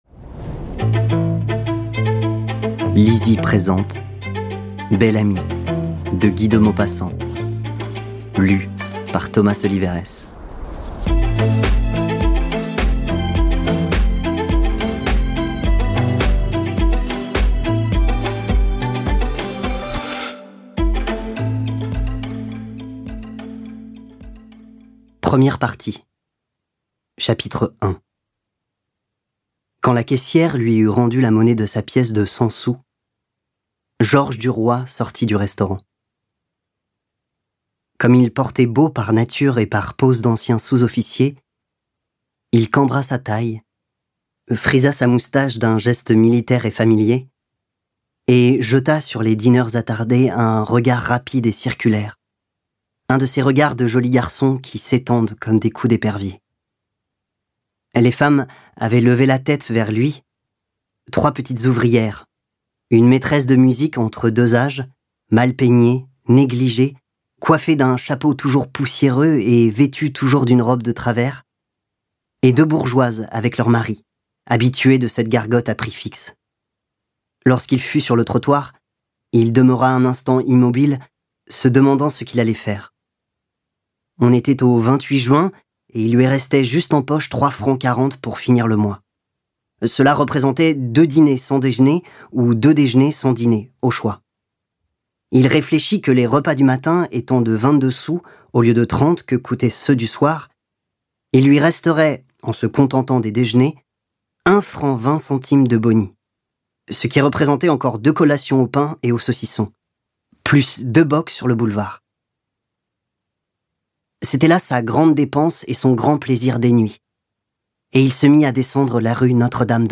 Lu par Thomas Solivéres.